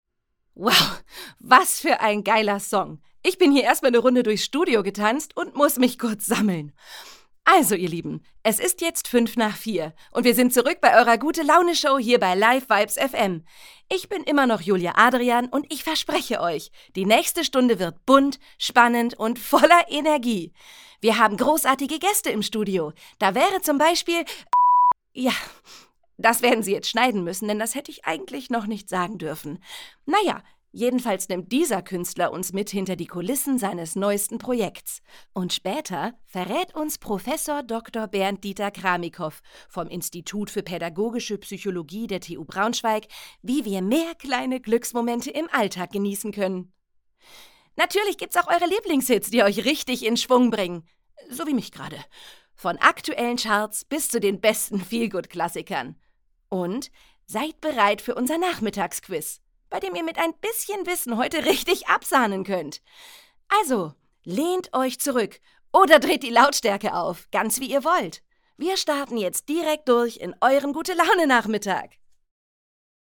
Sprecherin
Moderation, Demo